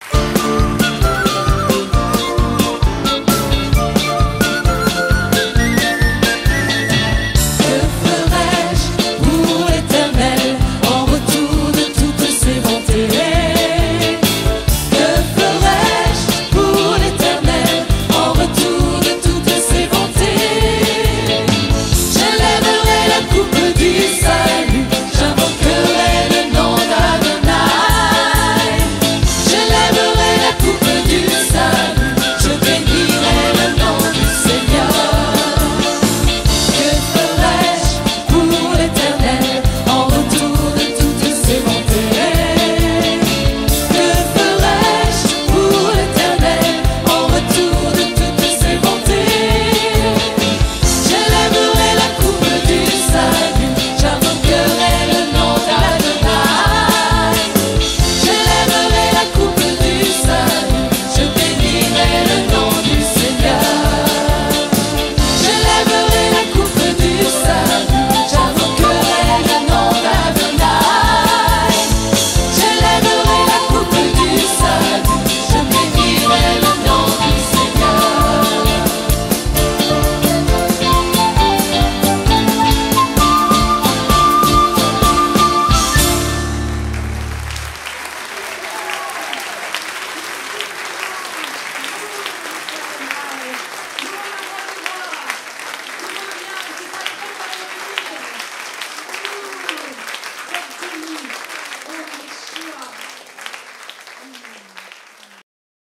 Chants de veillée et de louange